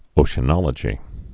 (ōshə-nŏlə-jē)